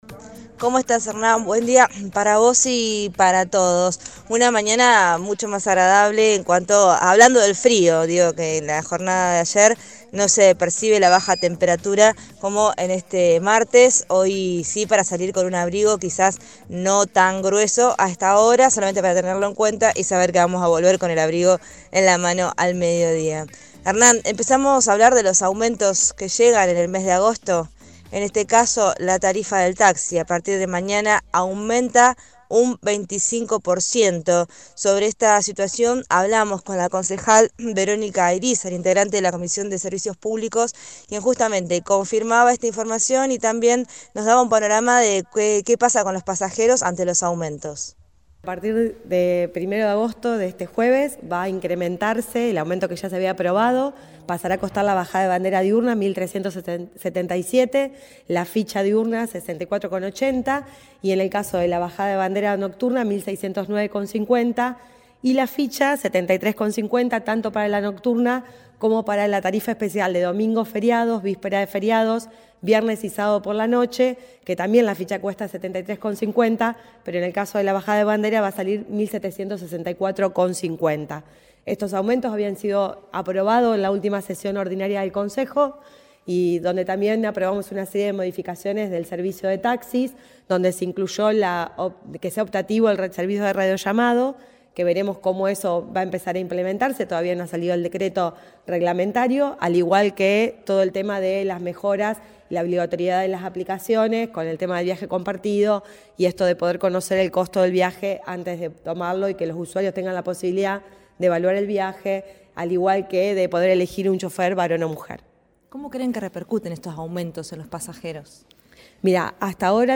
Verónica Irizar, concejala del Partido Socialista, habló este miércoles con el móvil de Cadena 3 Rosario, en Radioinforme 3, y explicó que "estos aumentos habían sido aprobados en la última sesión ordinaria del Concejo", y también se abordaron modificaciones en el servicio de taxis, como la opción de radiollamado, que aún espera su decreto reglamentario.